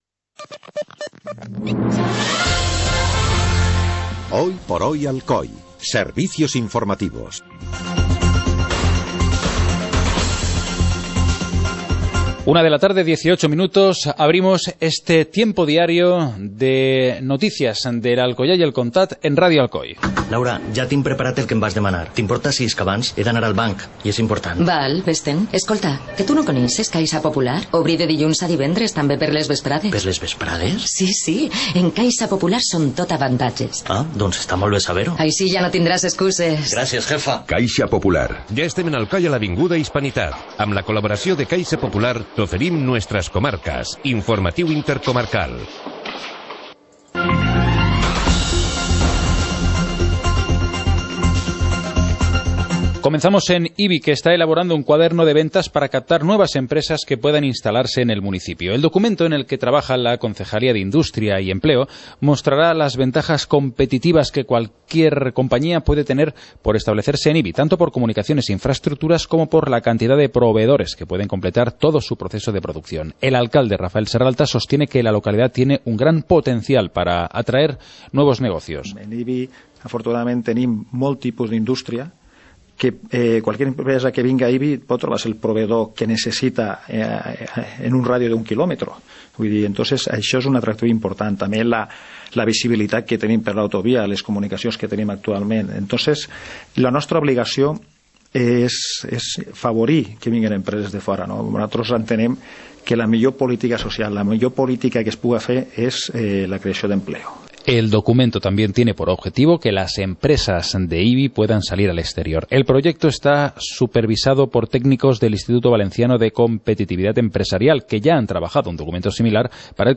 Informativo comarcal - miércoles, 21 de enero de 2015